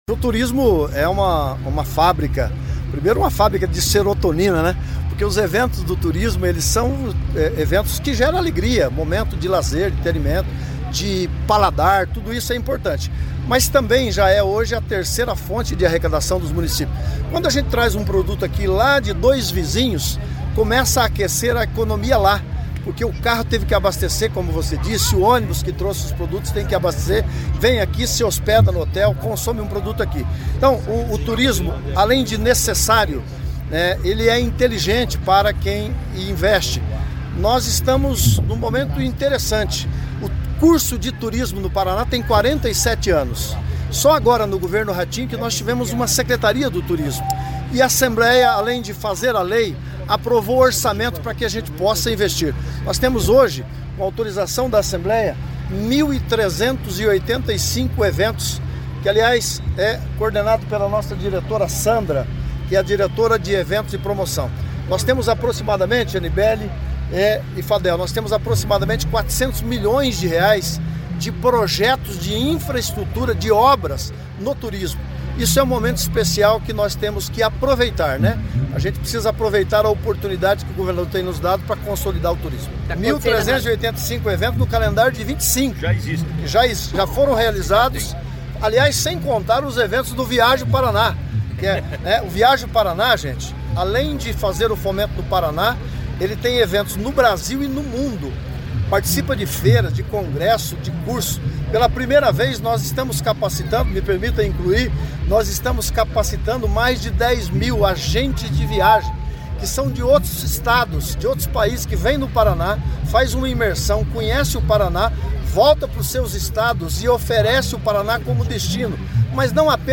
Sonora do secretário do Turismo, Leonaldo Paranhos, sobre o Festival da Primavera em Castro